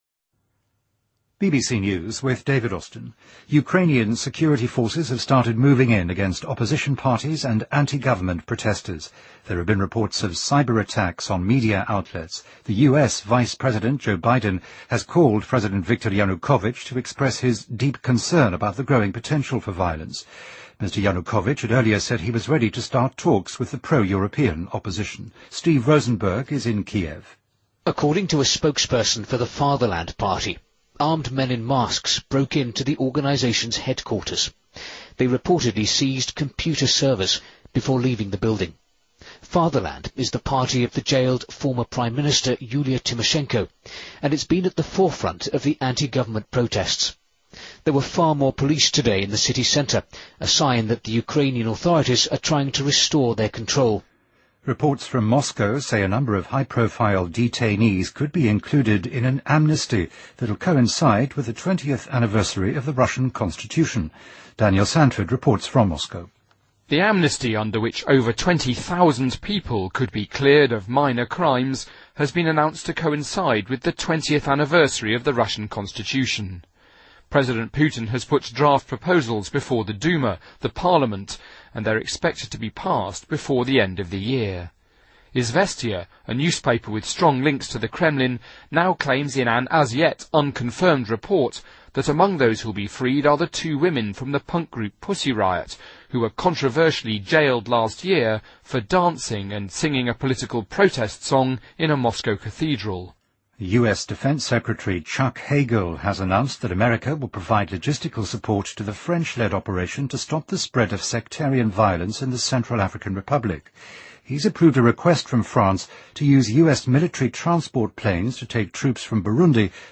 BBC news,俄罗斯宪法公投20周年之际大赦一批知名囚犯